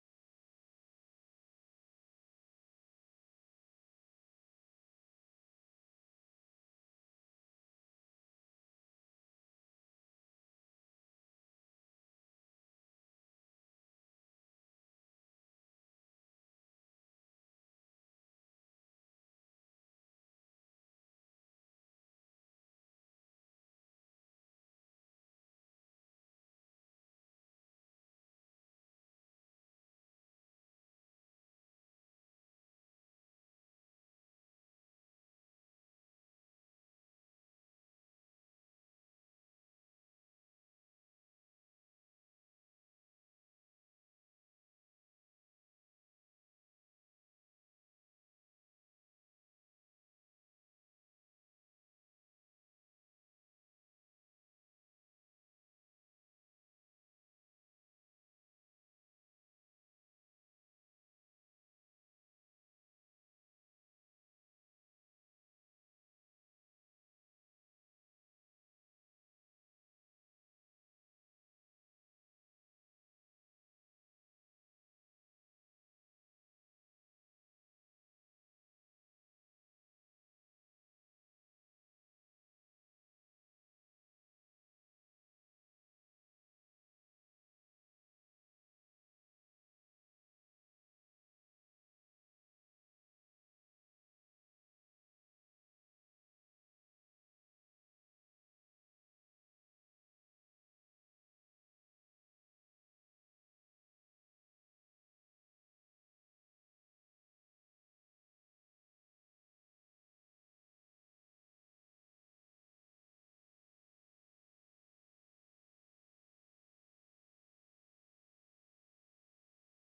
Аудиокнига Мужской поступок | Библиотека аудиокниг